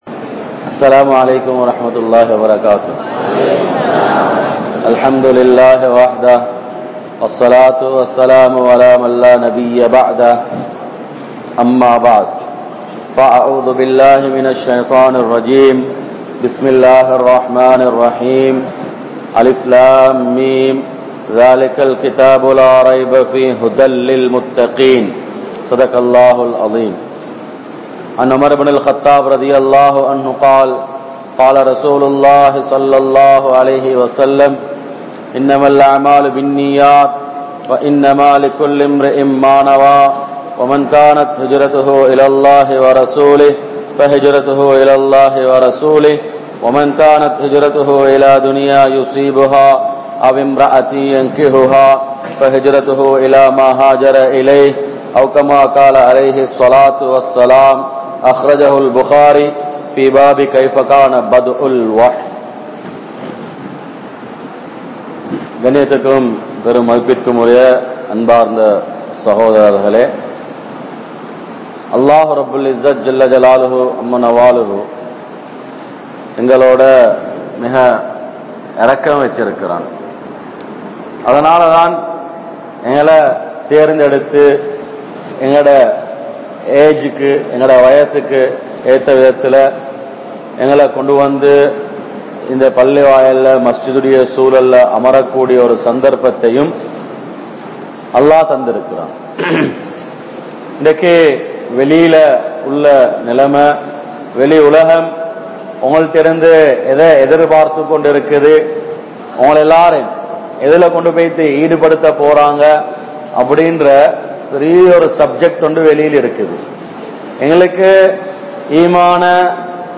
Islaaththai Marantha Muslim Vaalifarhal (இஸ்லாத்தை மறந்த முஸ்லிம் வாலிபர்கள்) | Audio Bayans | All Ceylon Muslim Youth Community | Addalaichenai